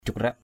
/d͡ʑʱuk-ra:ʔ/ (d.) bông quỳ = tournesol.